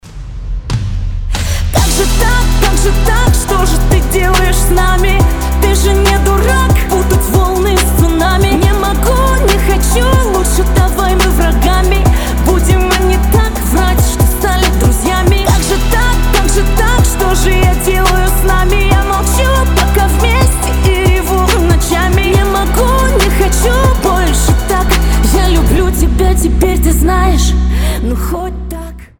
громкие
грустные
женский голос